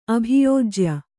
♪ abhiyōjya